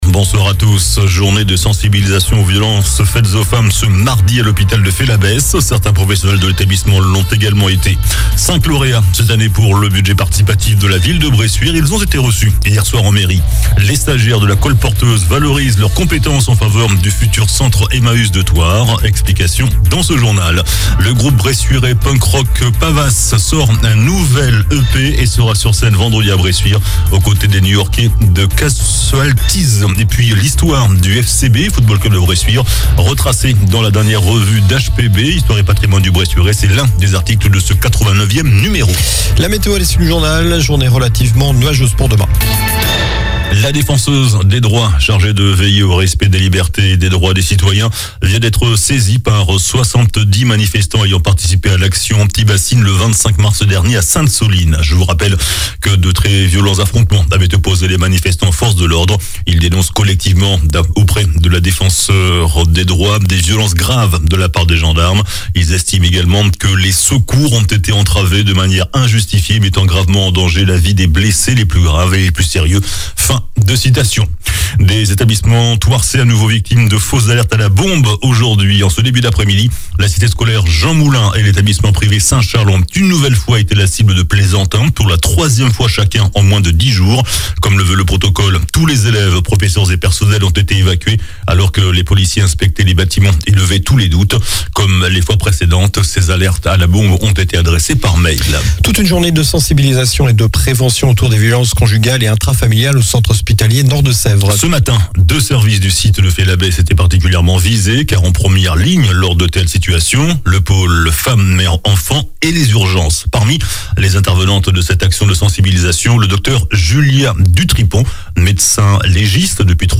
JOURNAL DU MARDI 05 DECEMBRE ( SOIR )